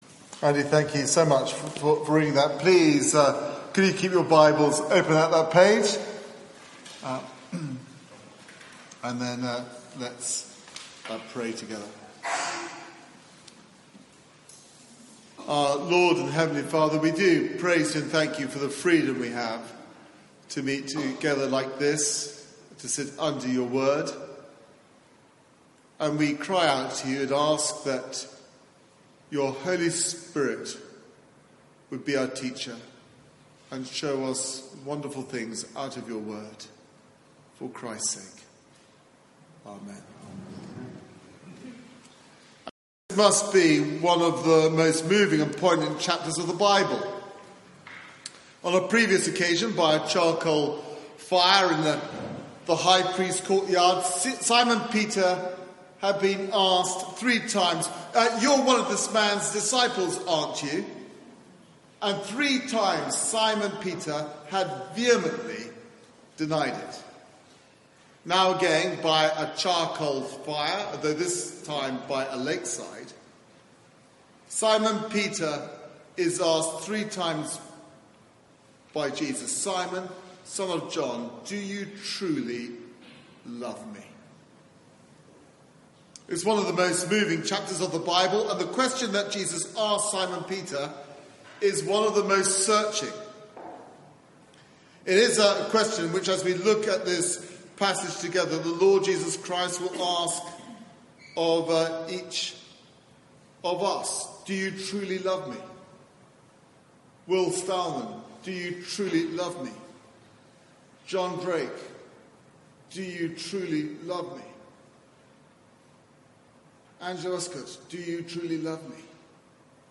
Sermon (11:00 Service) Search the media library There are recordings here going back several years.